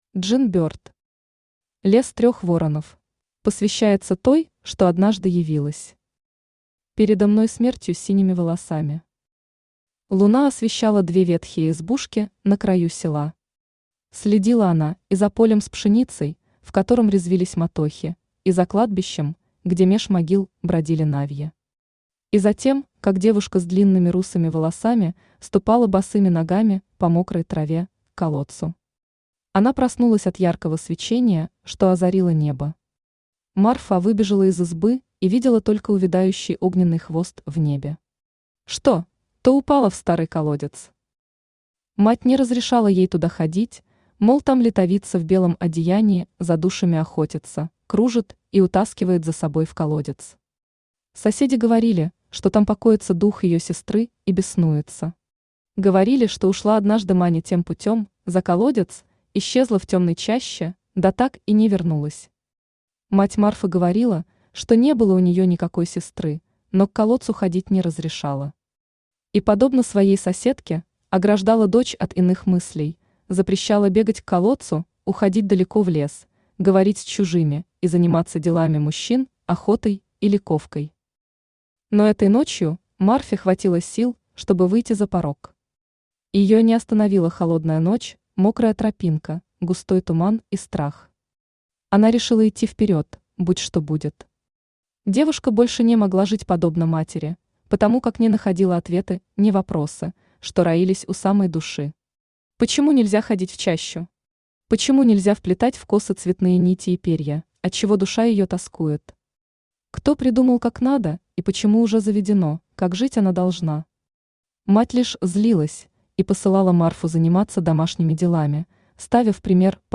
Aудиокнига Лес трех воронов Автор Джин Бёрд Читает аудиокнигу Авточтец ЛитРес. Прослушать и бесплатно скачать фрагмент аудиокниги